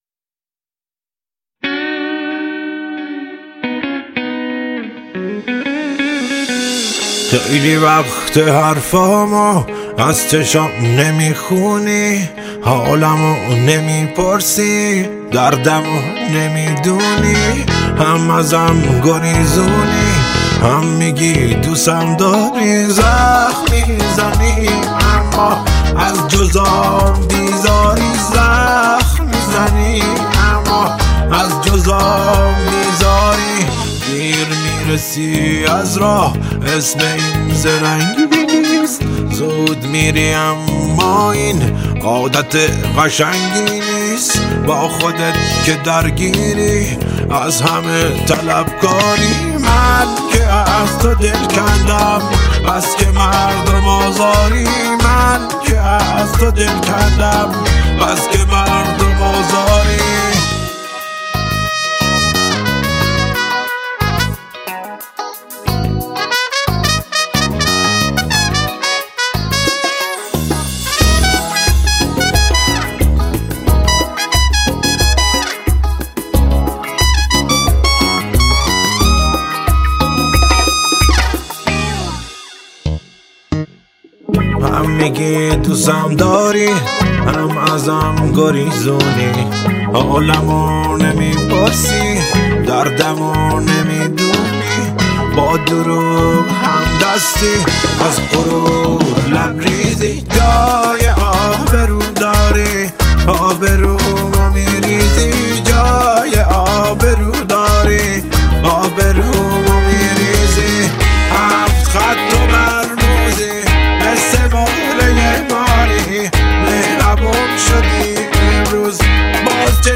با صدای دلنشین
این قطعه احساسی با شعری عمیق و ملودی جذاب